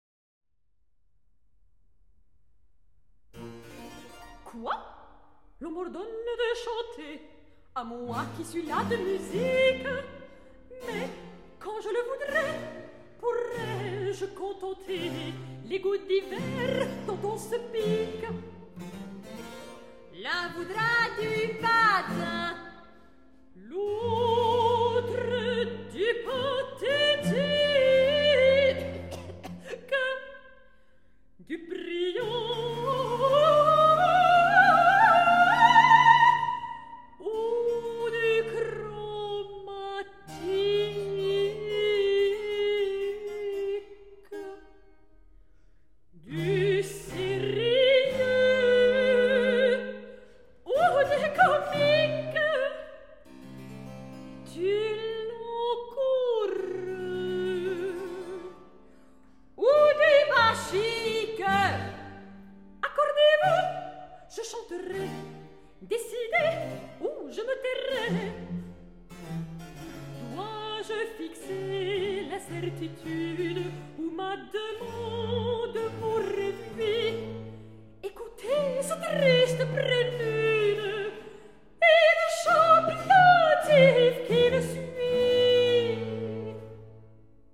violons
flûtes
violoncelle
guitare & théorbe.